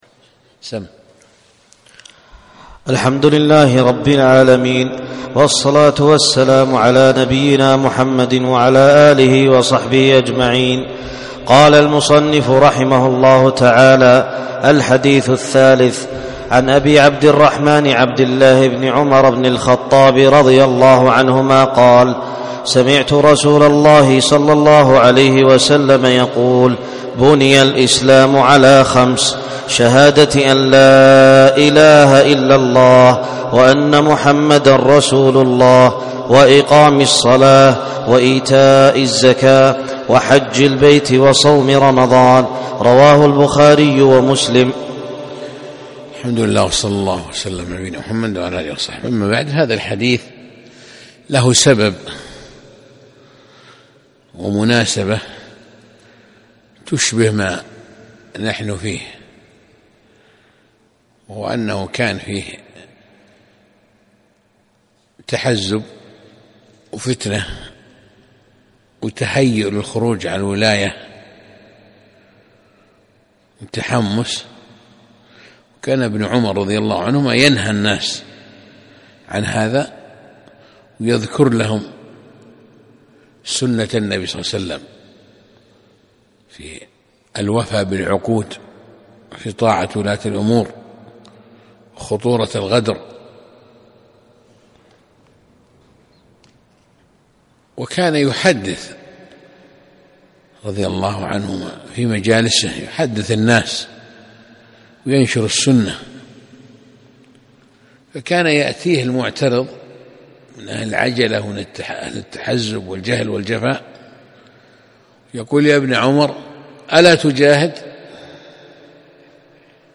يوم الخميس 16 جمادى الأولى 1437 في مسجد الشيخ
الدرس الثاني